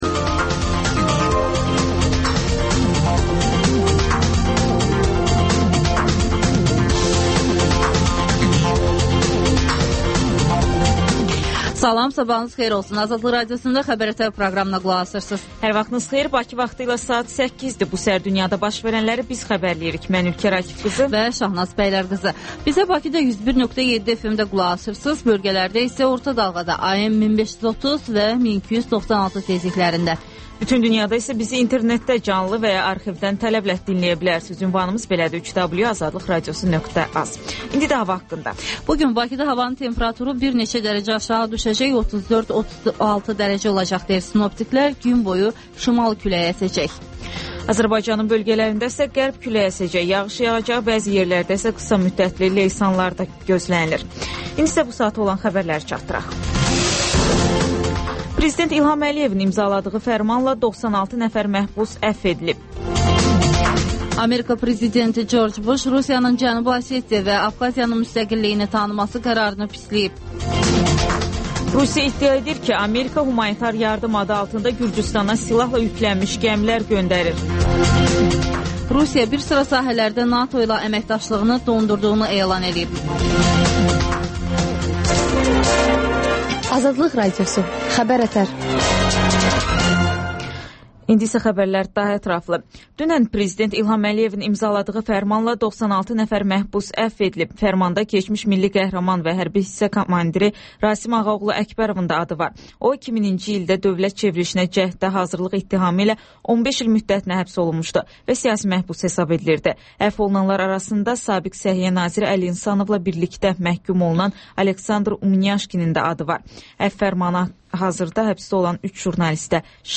Xəbər-ətər: xəbərlər, müsahibələr və ŞƏFFAFLIQ: Korrupsiya haqqında xüsusi veriliş